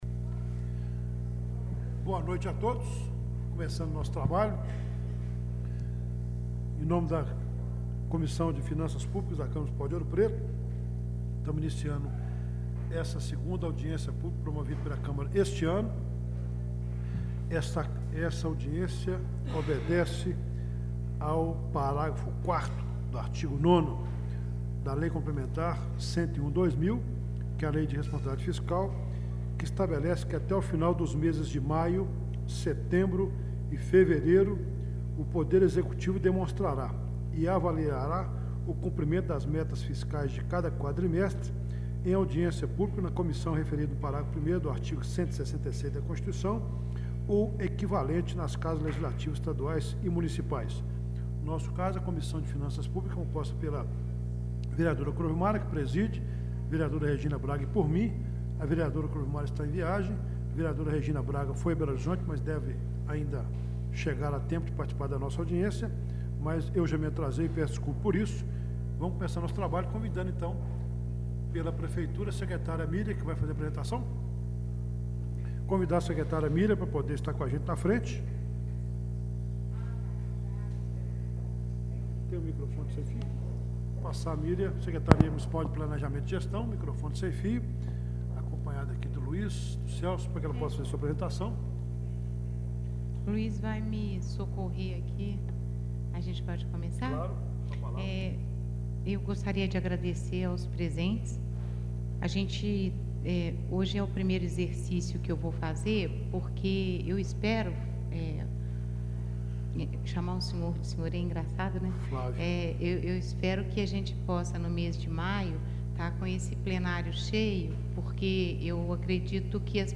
Áudio: | Câmara Municipal de Ouro Preto Audiência da Câmara Municipal promovida pela Comissão de Finanças Públicas para prestação de contas da Prefeitura referente ao 3° quadrimestre de 2008 Reunião Compartilhar: Fechar